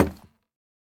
Minecraft Version Minecraft Version latest Latest Release | Latest Snapshot latest / assets / minecraft / sounds / block / bamboo_wood_hanging_sign / step2.ogg Compare With Compare With Latest Release | Latest Snapshot